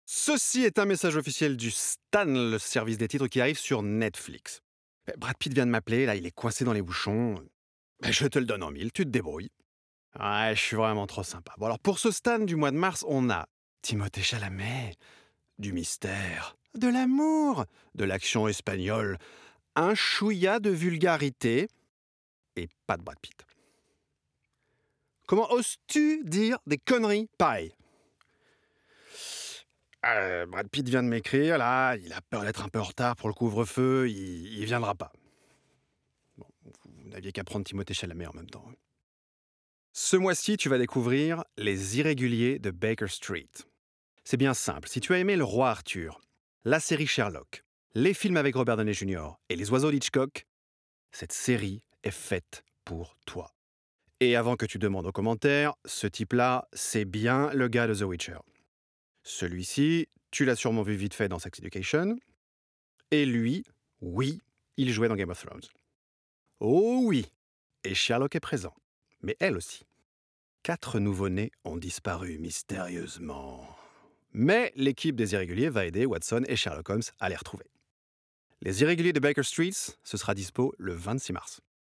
Voix off
Netflix bande annonce
28 - 48 ans - Baryton